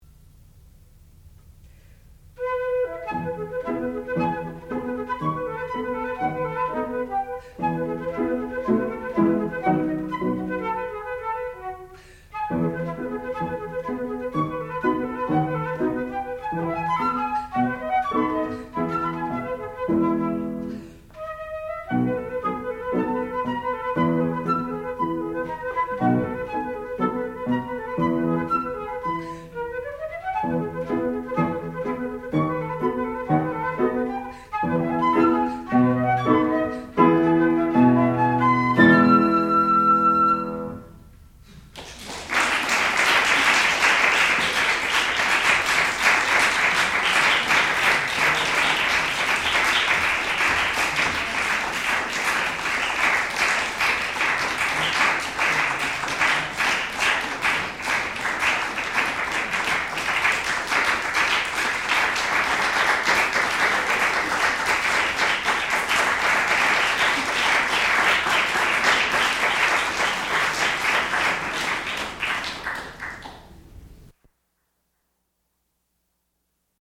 classical music
piano
Advanced Recital
flute